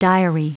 Prononciation du mot diary en anglais (fichier audio)
Prononciation du mot : diary